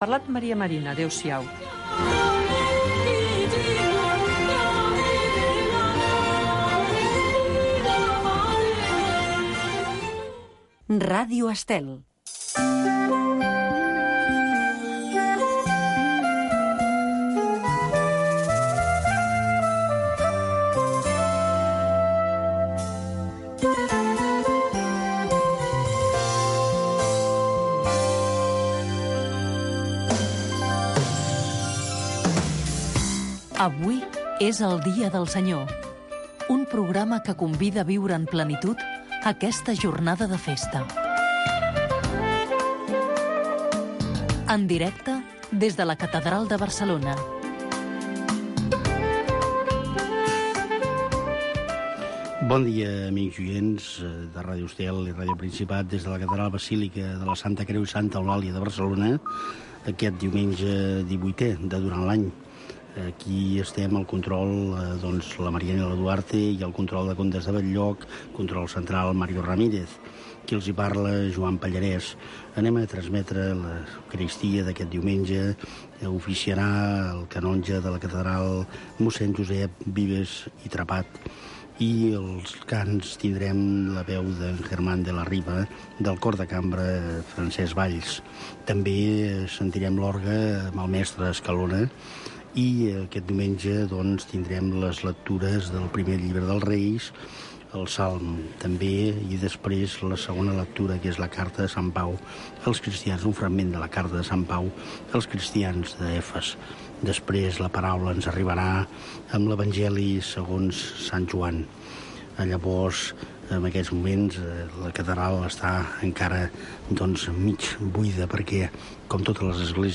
s de la catedral de Barcelona es retransmet tots els diumenges i festius la missa, precedida d’un petit espai d’entrevista